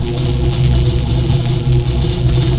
ZZAP_HUM.WAV